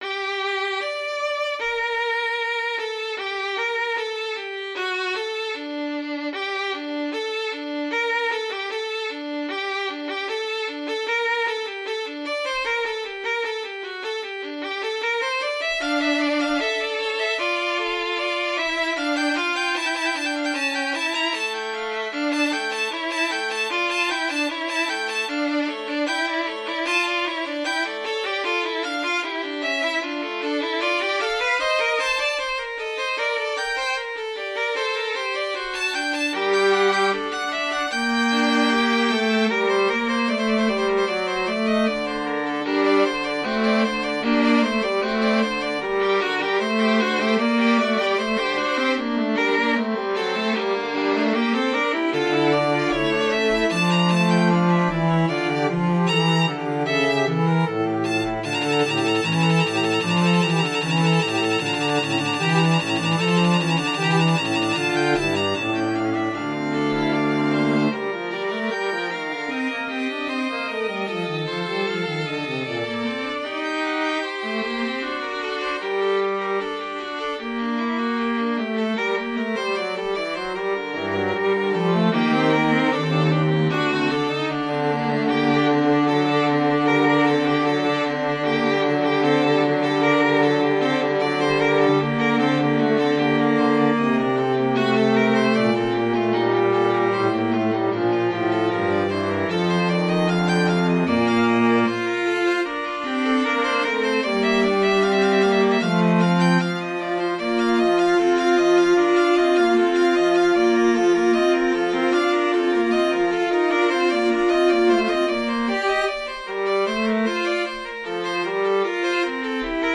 for String Quartet
Voicing: String Quartet